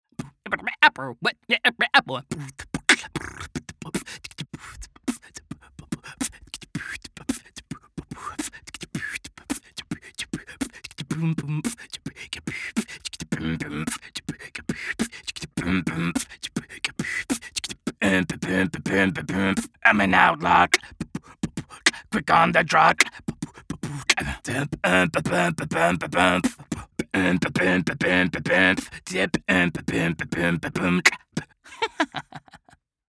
Beatbox Demo